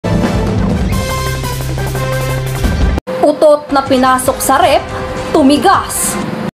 Play, download and share utot napinasok sa reff tumigas original sound button!!!!